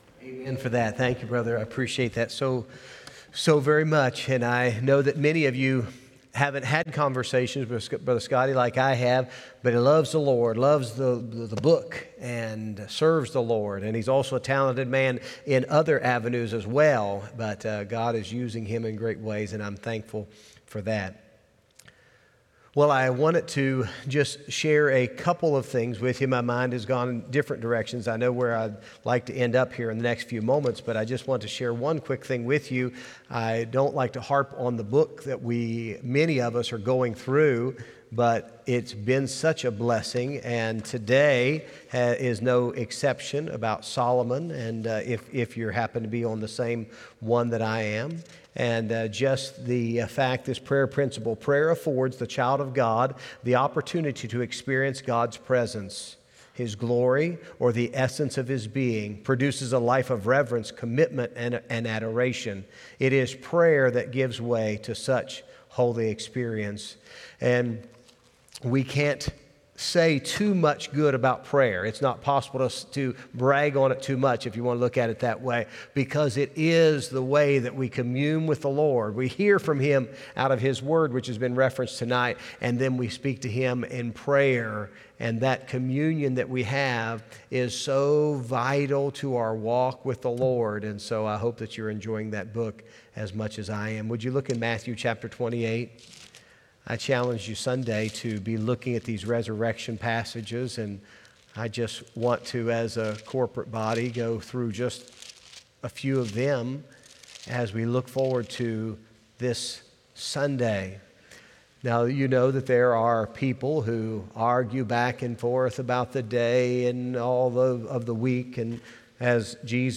Wednesday evening service